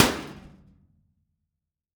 Impact Metal Impact Trash Can Hit 01.wav